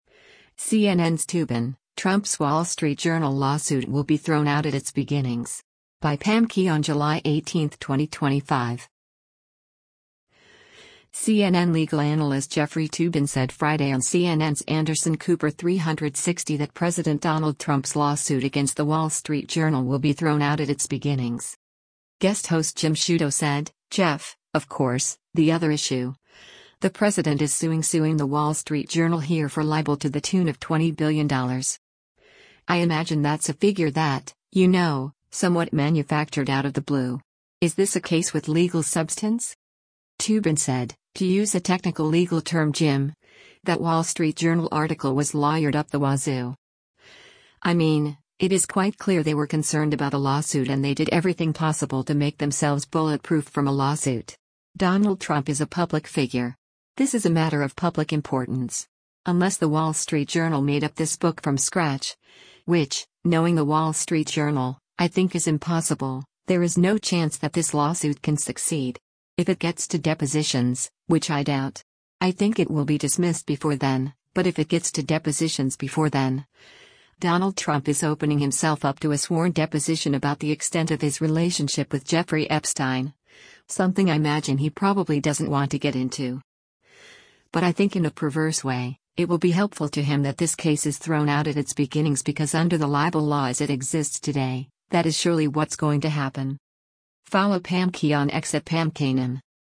CNN legal analyst Jeffrey Toobin said Friday on CNN’s “Anderson Cooper 360” that President Donald Trump’s lawsuit against The Wall Street Journal  will be “thrown out at its beginnings.”